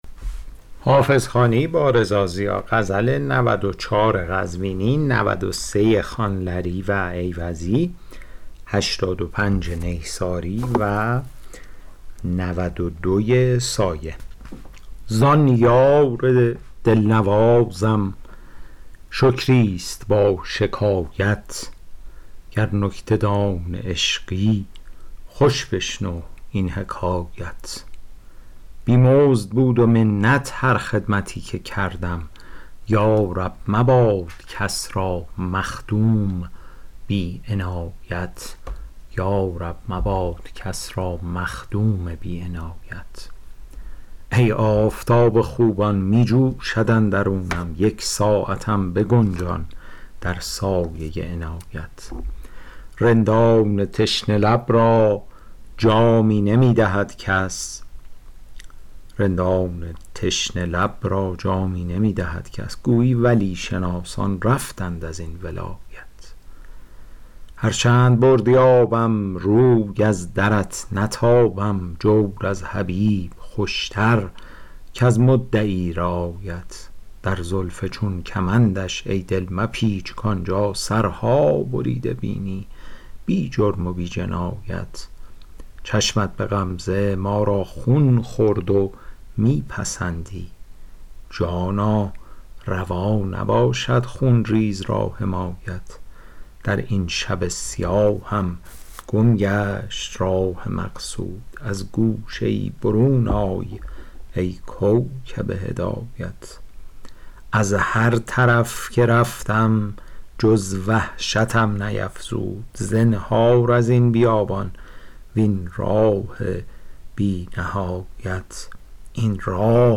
شرح صوتی غزل شمارهٔ ۹۴